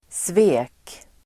Uttal: [sve:k]